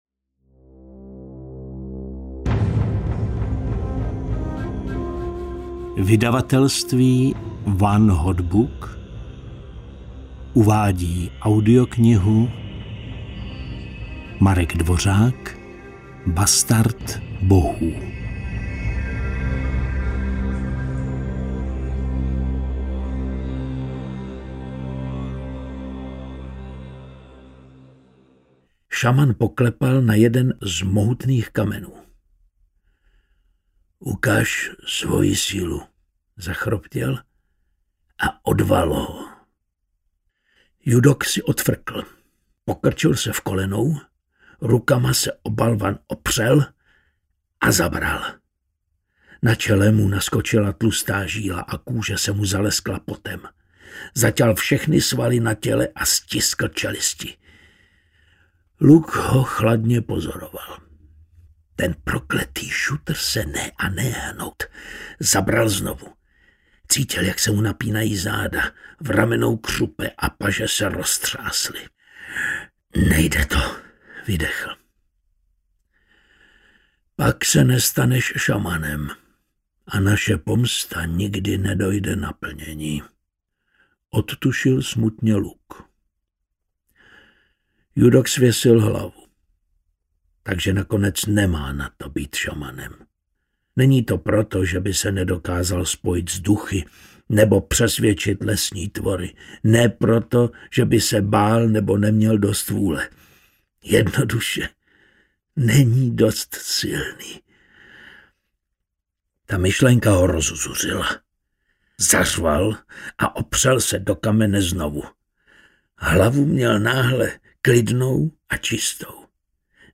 Bastard bohů audiokniha
Ukázka z knihy